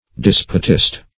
Despotist \Des"po*tist\, n. A supporter of despotism.